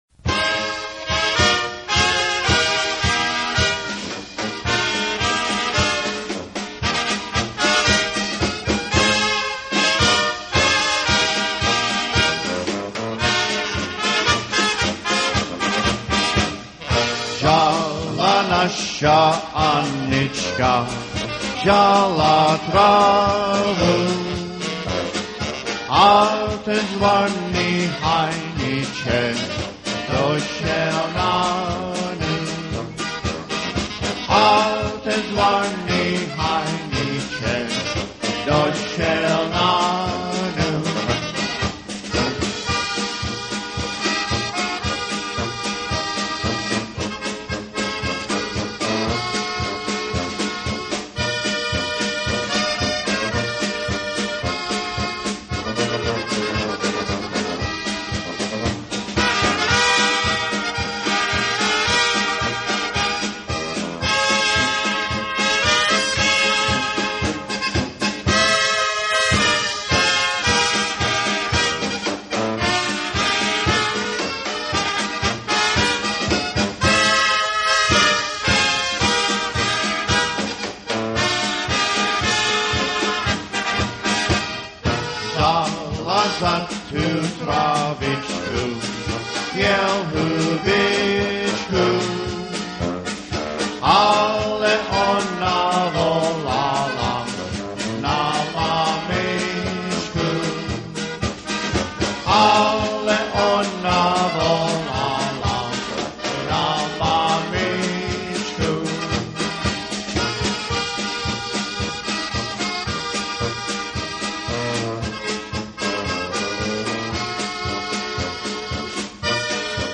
Commentary 8.